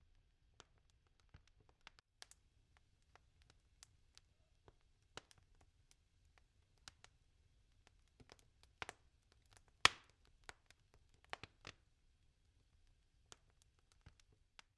燃烧的火焰
描述：燃烧火焰的良好氛围噪音
Tag: 噼啪作响 壁炉 氛围 是bient 火焰 火花 裂纹 烧伤 火焰 燃烧